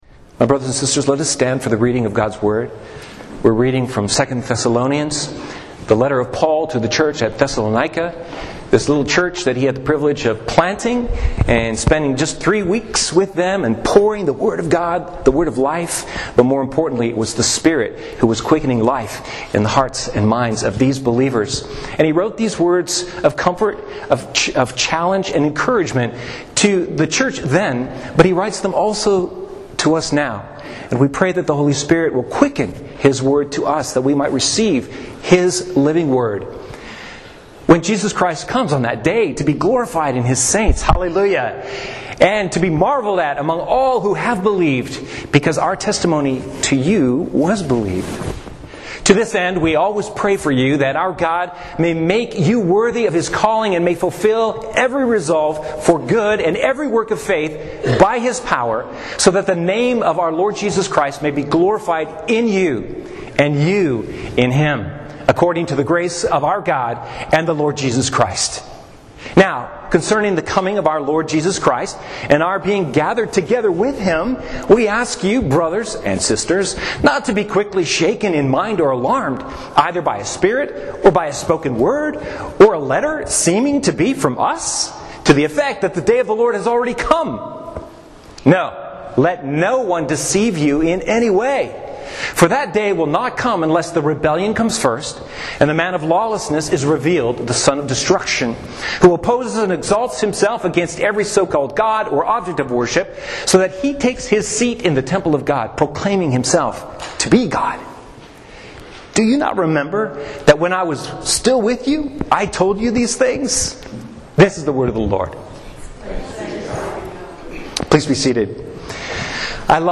Sermon, “End Times Faithfulness” 10-23-22